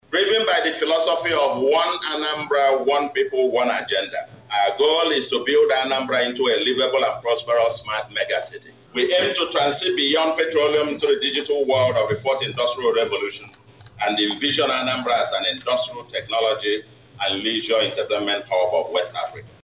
Speaking at the swearing-in ceremony, Soludo noted that his goal is to build Anambra into a mega city.